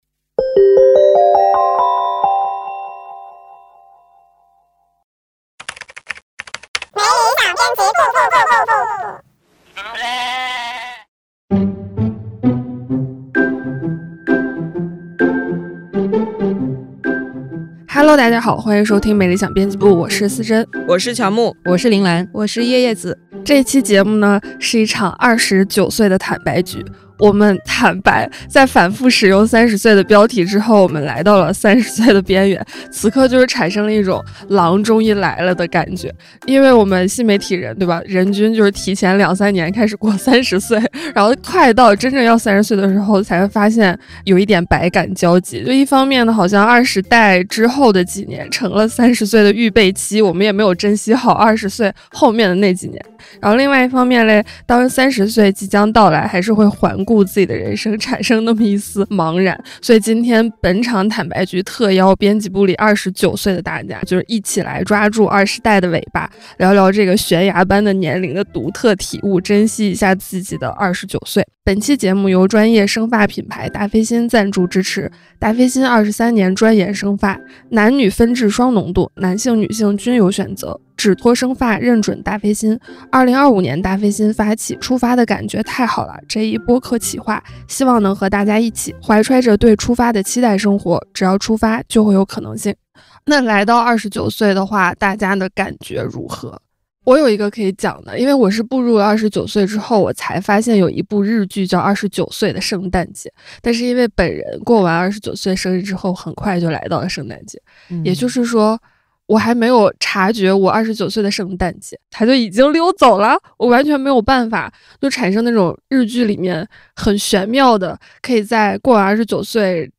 「没理想编辑部」是由「看理想」新媒体部出品的谈话类生活文化播客，a.k.a编辑们的午饭闲聊精选集，欢迎入座。